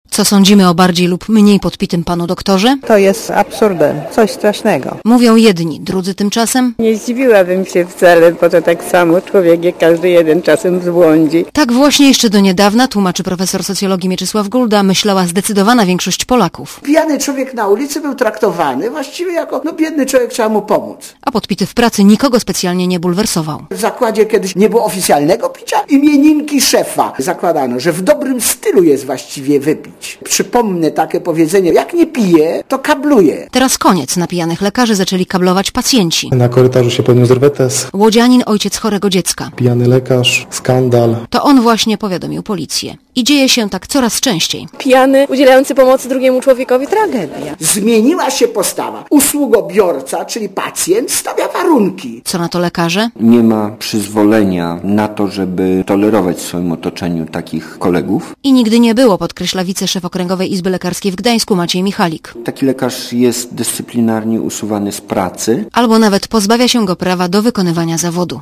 Posłuchaj relacji korespondentki Radia Zet (0.5 MB)